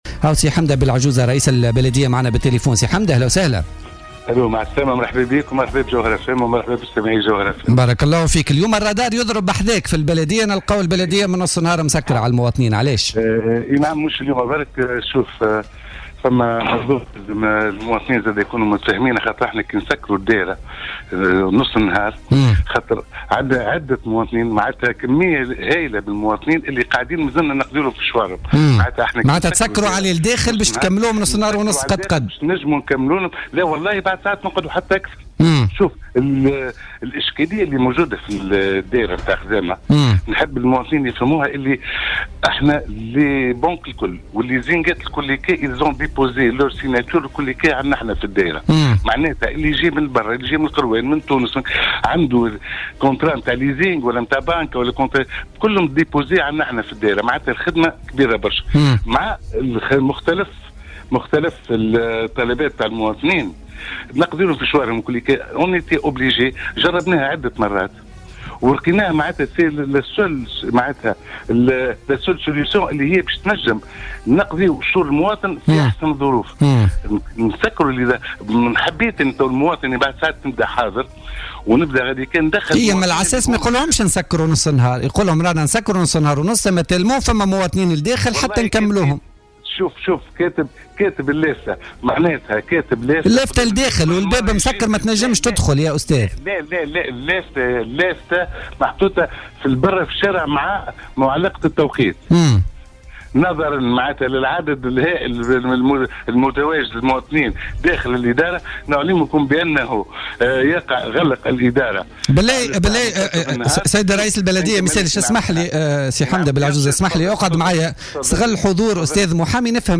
وأوضح حمدة بلعجوزة رئيس بلدية خزامة في مداخلة له على أمواج الجوهرة أف أم أن إغلاق أبواب البلدية على الساعة منتصف النهار يتم اضطراريا نظرا للعدد الهائل من المواطنين الذين يتوافدون على البلدية خاصة في النصف ساعة الأخيرة من العمل مضيفا أنه يتم الاكتفاء بقضاء شؤون عدد محدد من المواطنين في هذا التوقيت.